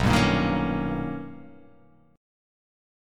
B+M9 chord